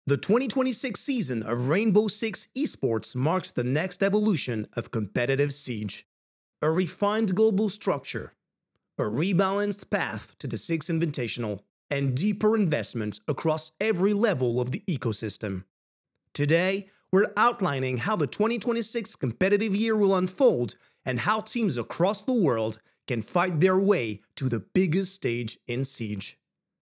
Voix off
10 - 35 ans - Contre-ténor